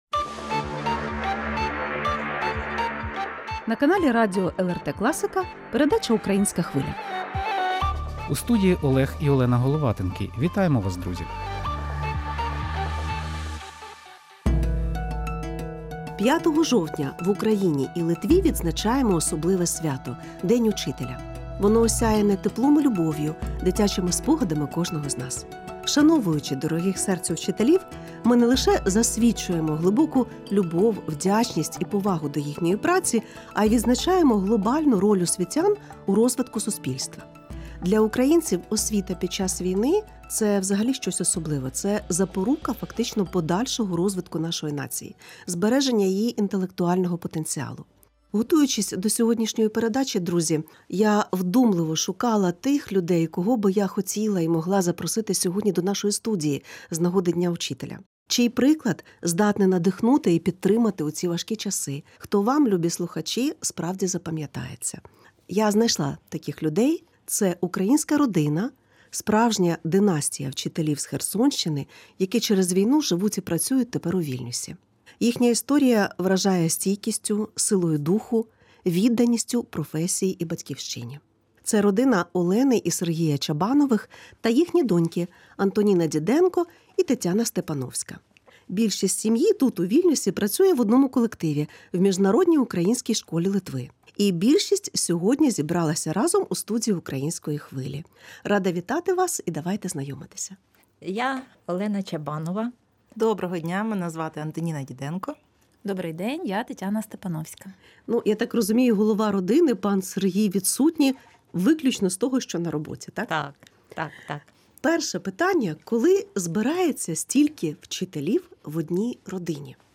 З нагоди професійного свята говоримо з освітянками про те, як не розгубити любов до дітей та душевне тепло у час війни. Що нового і корисного дав досвід вчителювання у Литві?